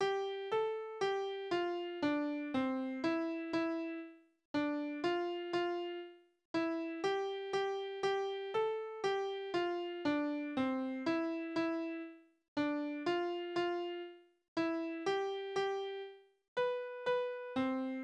Kindertänze: Mariechen auf dem Stein
Tonart: C-Dur
Taktart: 4/4
Tonumfang: große Septime